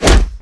WAV · 36 KB · 立體聲 (2ch)